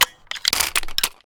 dragonov_reload.wav